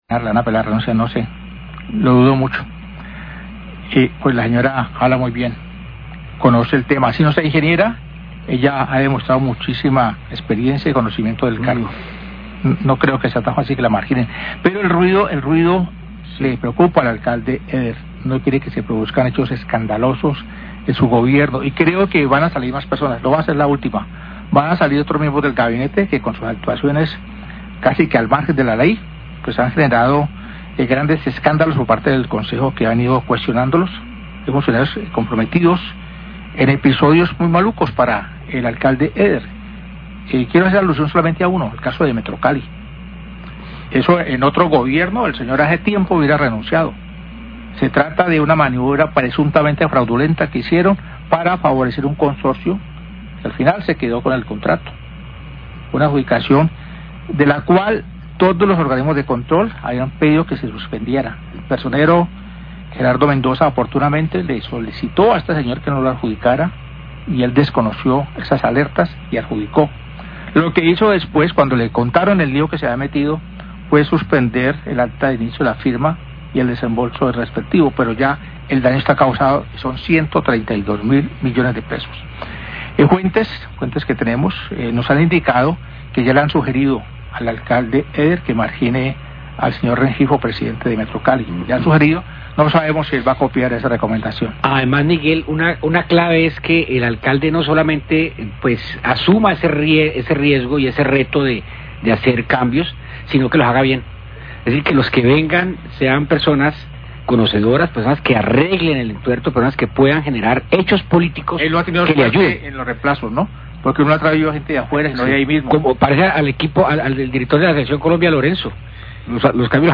NOTICIERO RELÁMPAGO